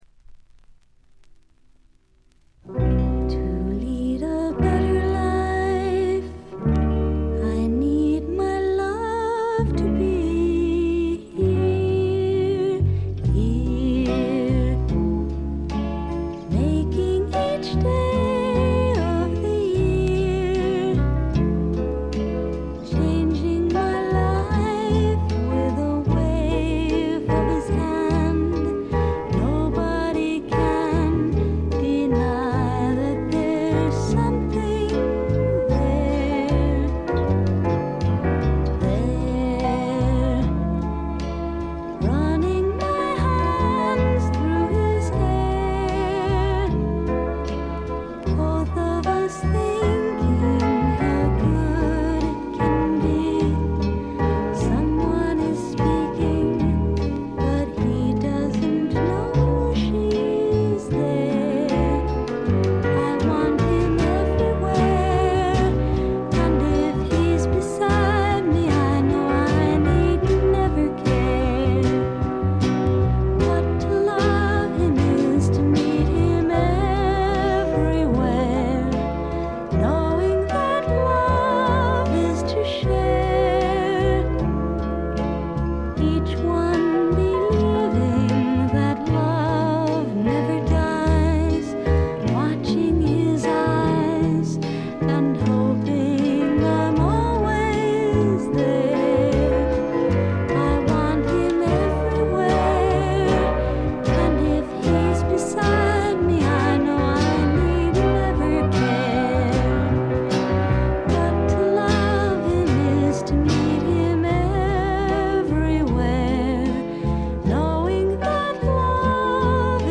ところどころで軽いチリプチ。
ドリーミーなアメリカン・ガールポップの名作！
試聴曲は現品からの取り込み音源です。